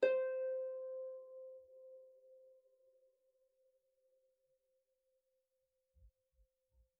KSHarp_C5_mf.wav